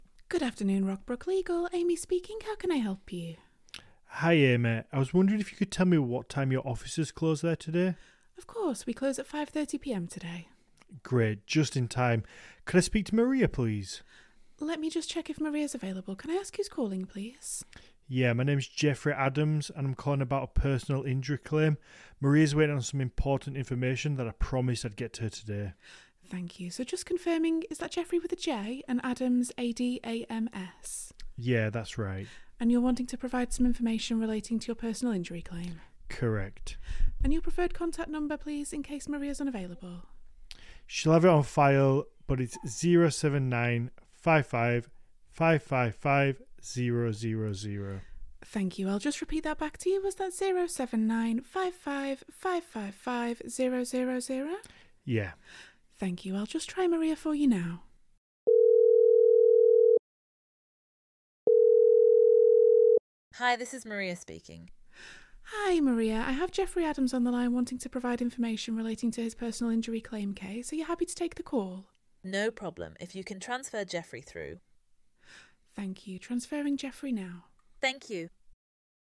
lawyer-answering-service-sample-call.mp3